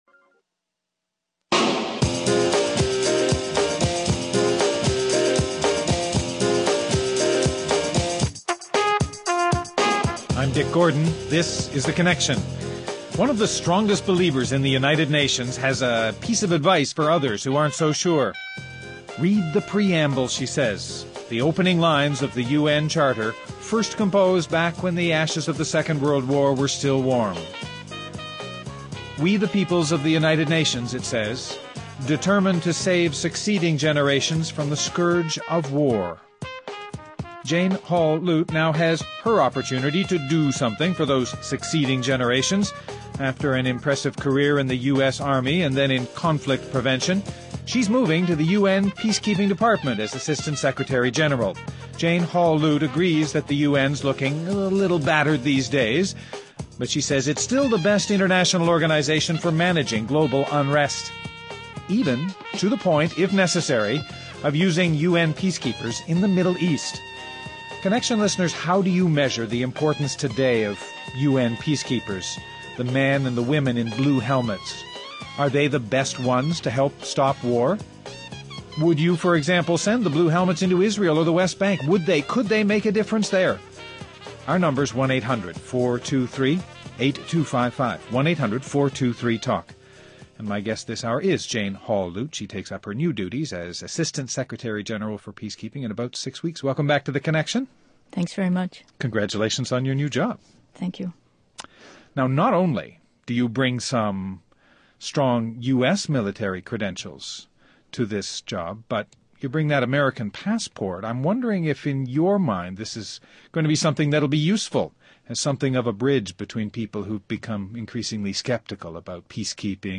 Guests: Jane Holl Lute, United Nations Assistant Secretary-General for Mission Support in the Department of Peacekeeping-designate Robert Oakley, former U.S. Ambassador.